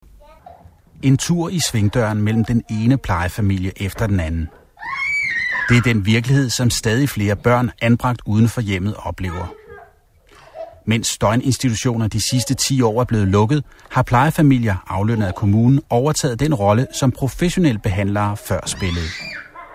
Danish voice over artist.
Sprecher dänisch. Clear dark male voice.
Voice over talent danish. Clear dark male voice.